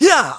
Riheet-Vox_Attack2.wav